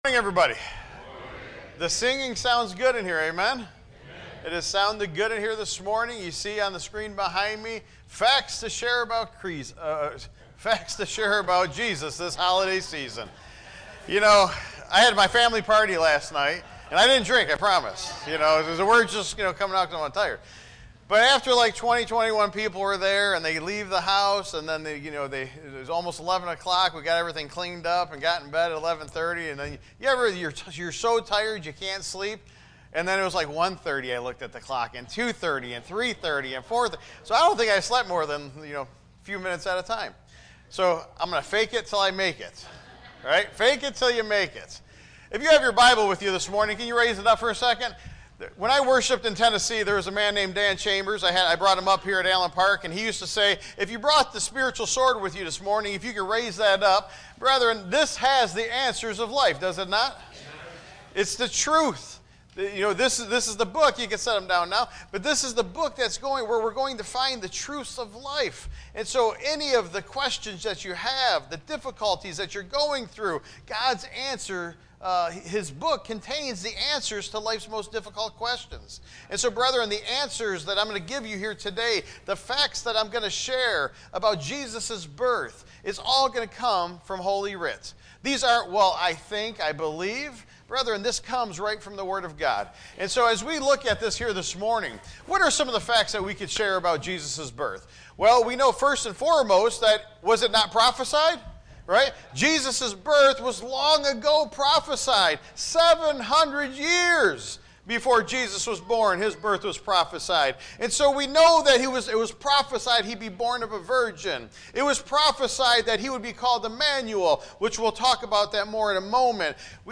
Tagged with sermon Audio (MP3) 12 MB Previous To Live is Christ Next Live in Peace